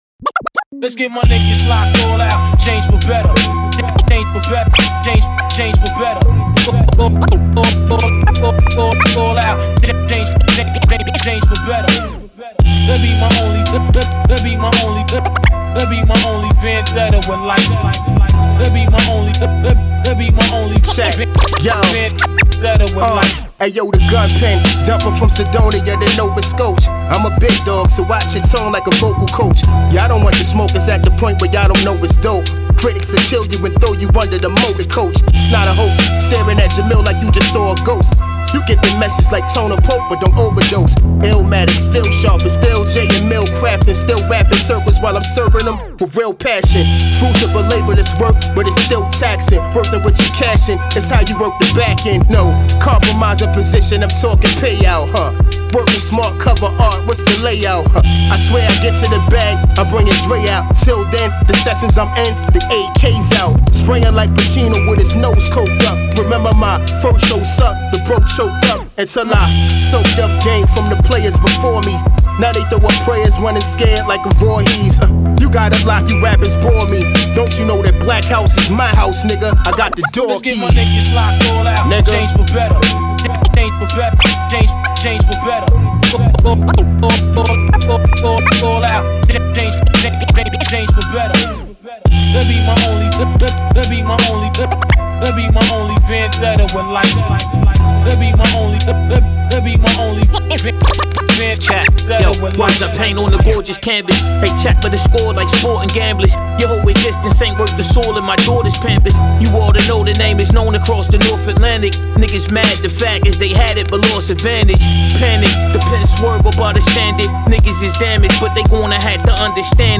Pure East Coast pressure.